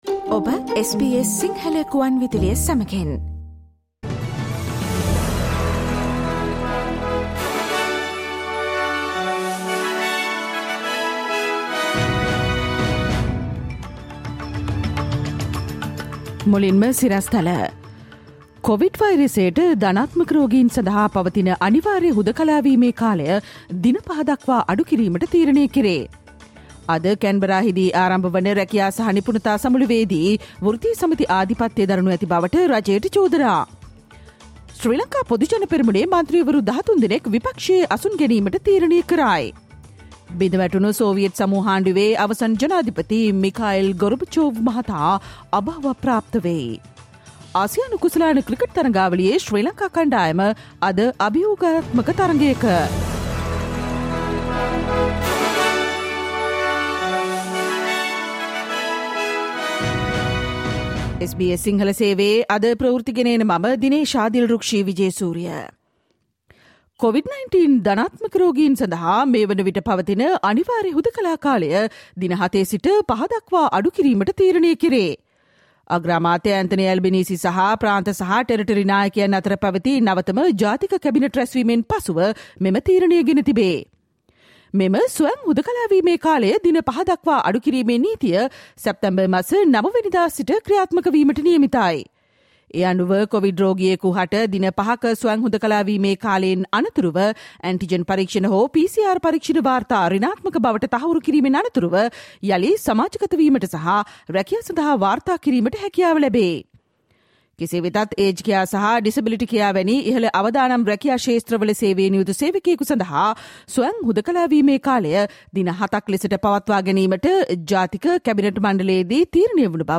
Listen to the SBS Sinhala Radio news bulletin on Thursday 1 September 2022